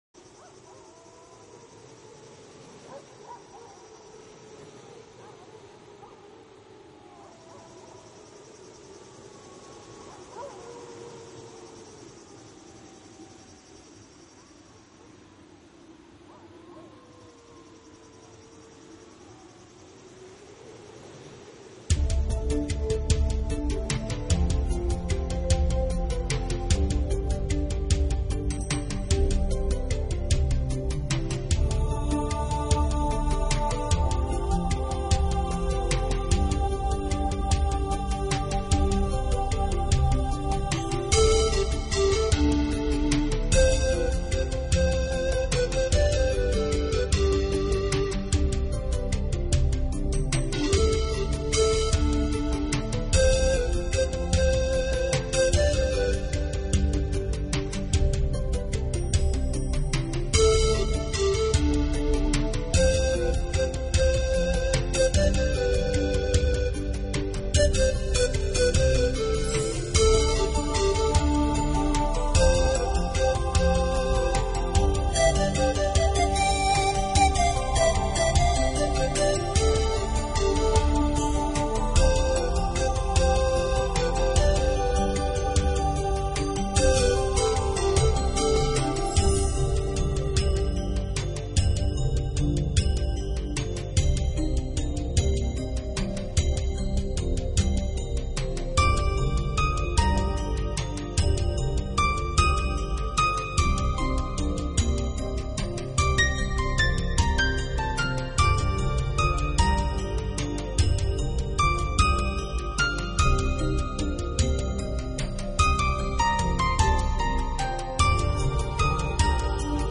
专辑类型：New Age
的音乐就象有一个真正的守护神坐阵，原始，纯真的感觉源源不断地激发。
同时，附以电子键盘点击的衬托，清脆而嘹亮，高亢！